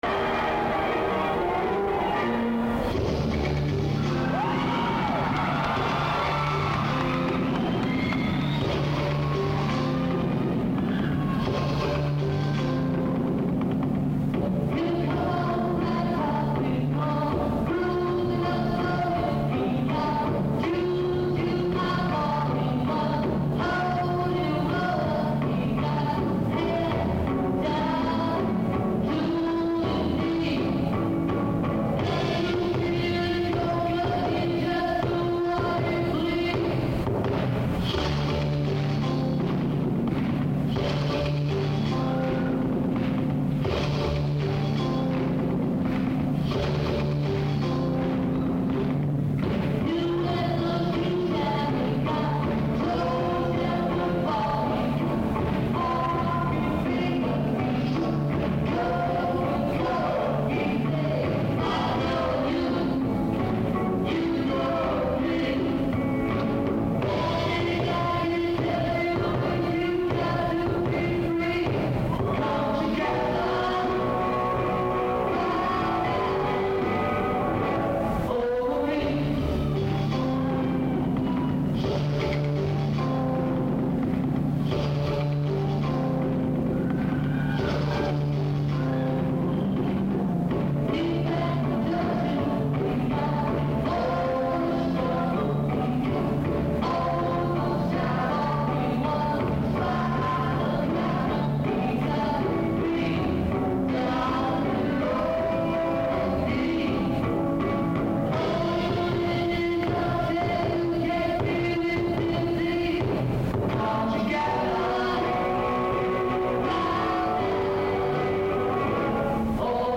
Guitar, Piano, Bass